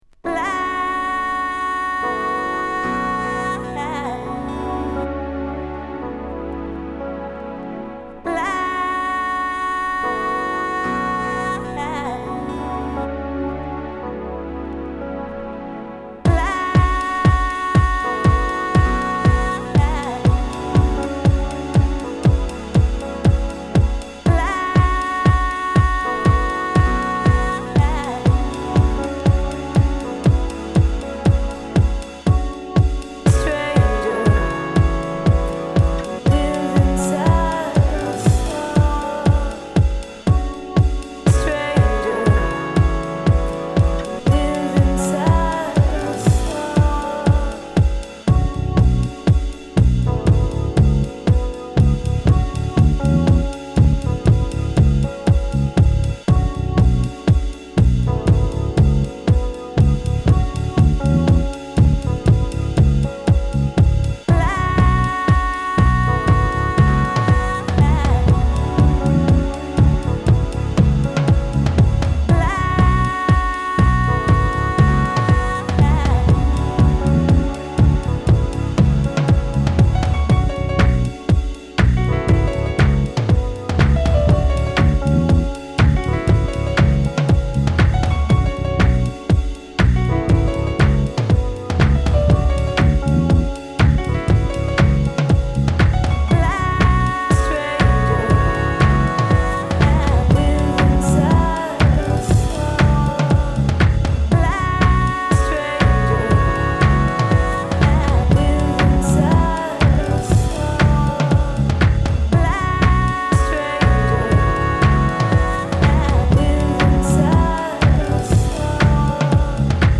フュージョン・エレクトリックな
煌めくシンセのリフレインと程よくローファイなヴォイス・フレーズがエレクトリック・ソウル・ヴァイブを育む
Deep House